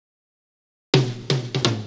Fill 128 BPM (15).wav